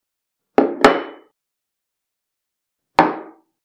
Чашку на стол